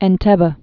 (ĕn-tĕbə, -tĕbē)